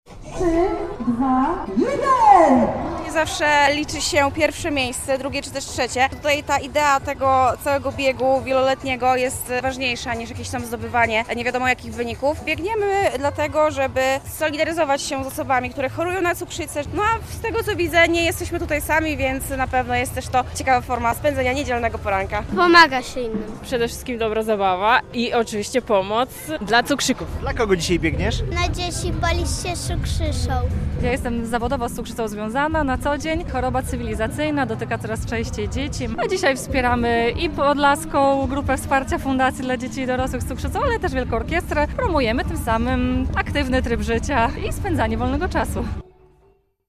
Bieg Policz się z Cukrzycą w centrum Białegostoku - relacja